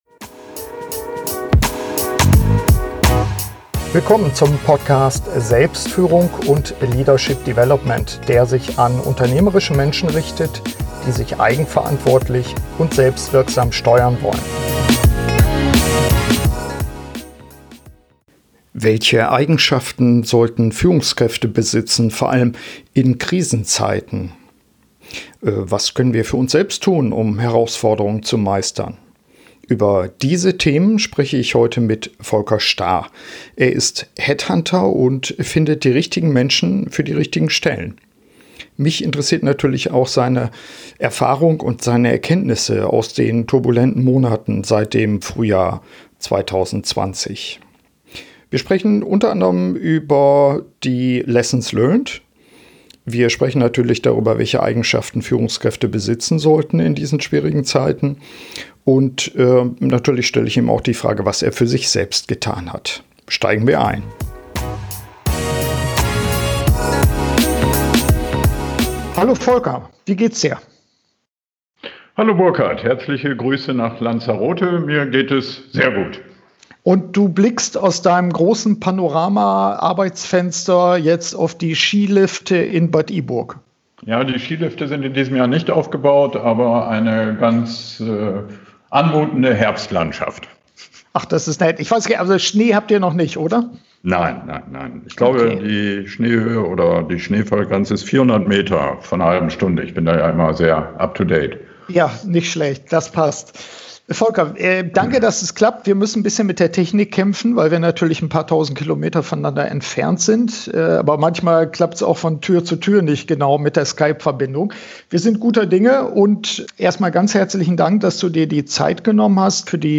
Updategespräch